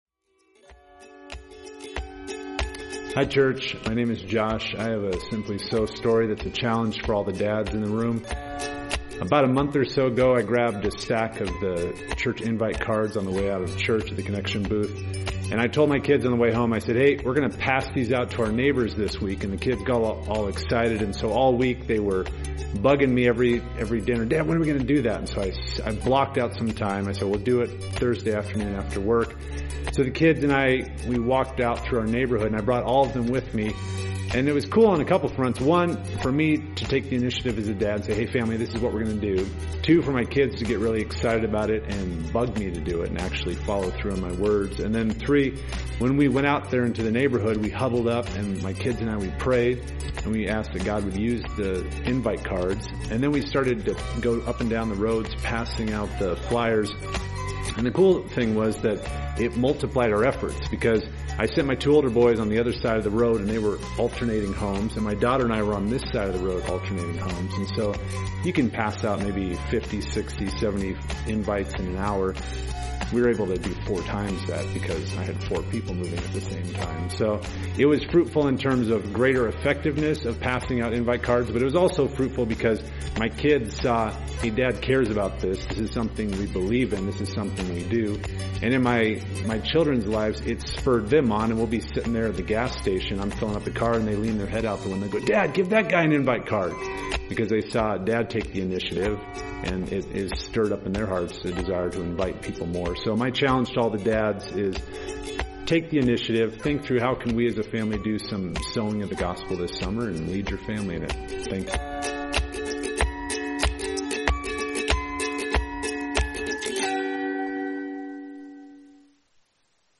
A message from the series "The Church."